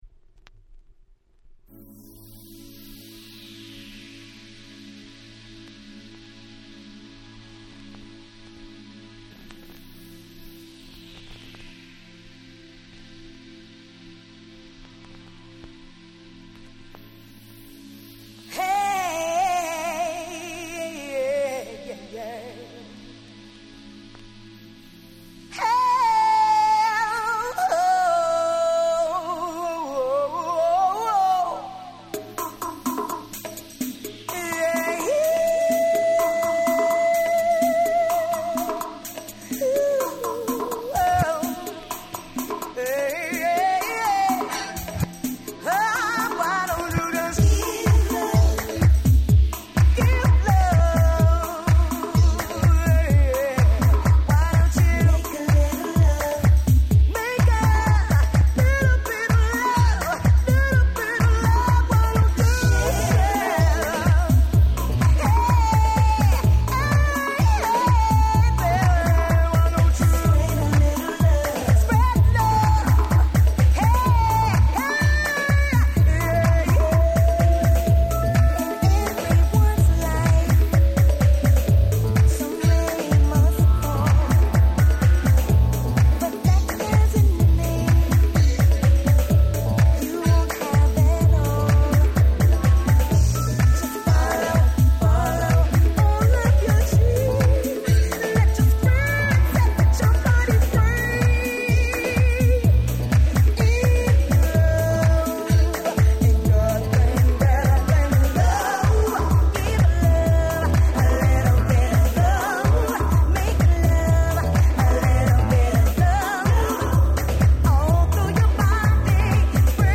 98' Nice Japanese House !!
After Hoursにも対応した美しい落ち着いたVocal House !!